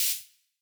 IBI Snare.wav